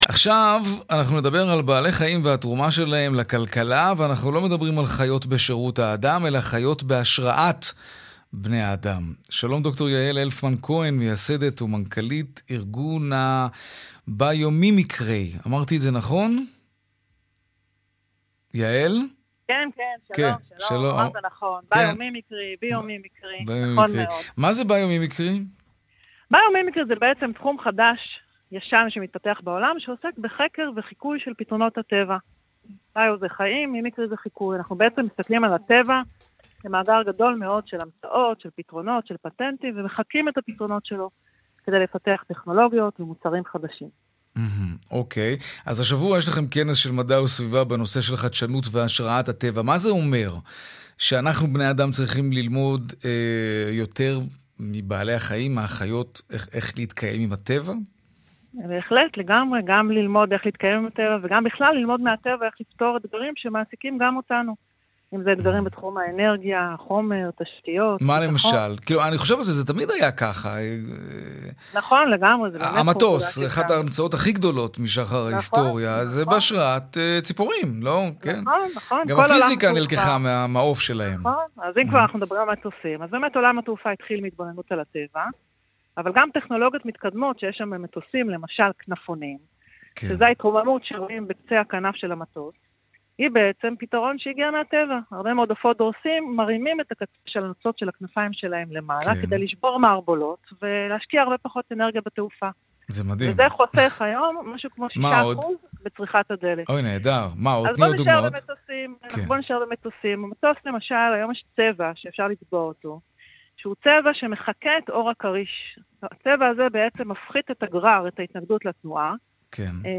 קישור לראיון